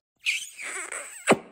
Звуки эмодзи
Поцелуй со звуком смайлика